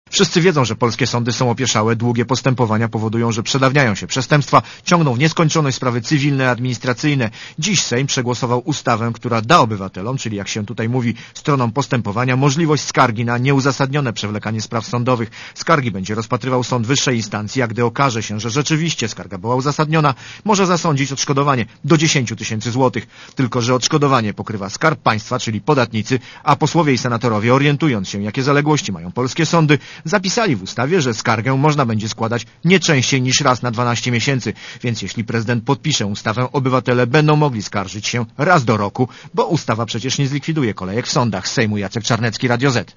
reportera Radia ZET